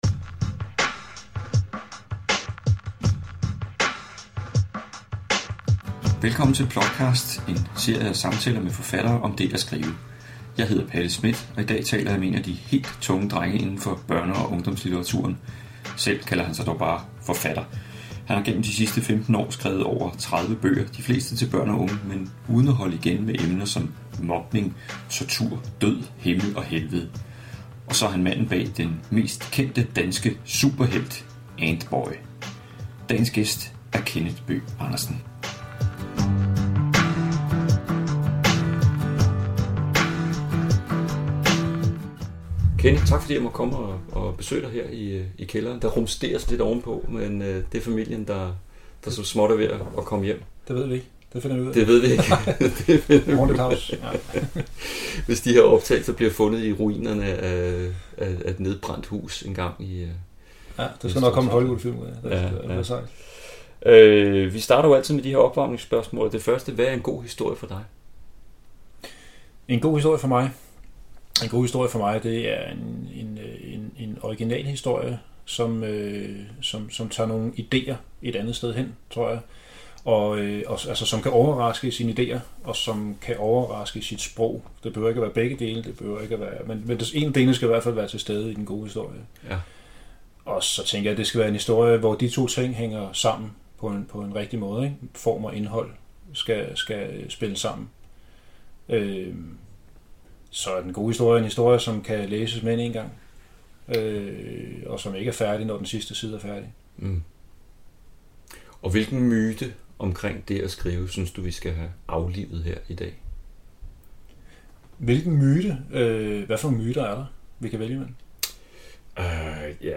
I dette interview taler vi om hans skriveproces, om læring ved at oversætte egne bøger til film og hvorfor han dyrker død og vold, himmel og helvede i sine bøger.